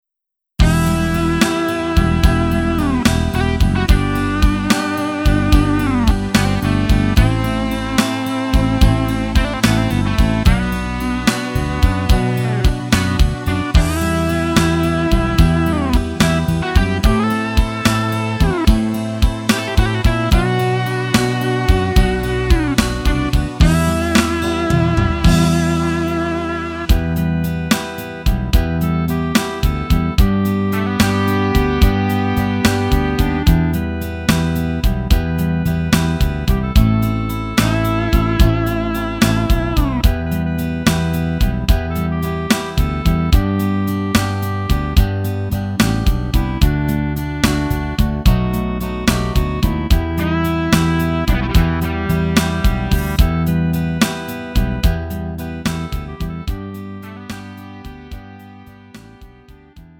음정 원키 3:53
장르 구분 Lite MR